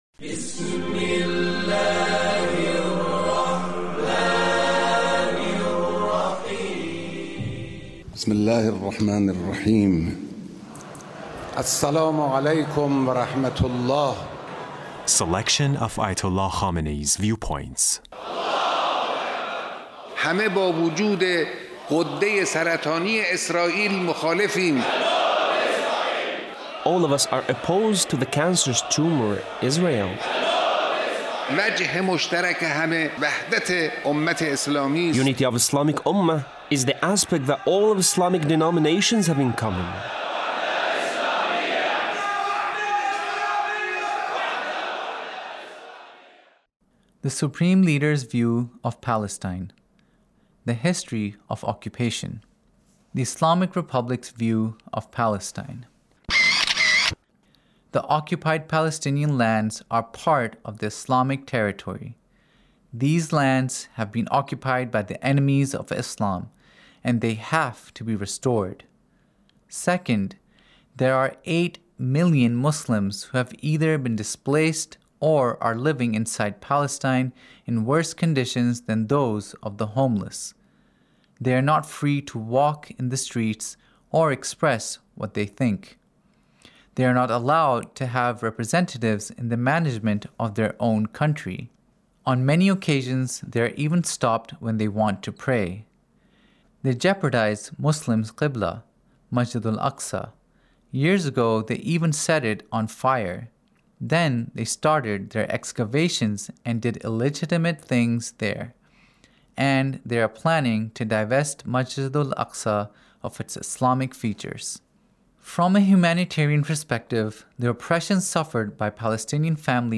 Leader's Speech in a Meeting with the Three Branches ofGovernment Repentance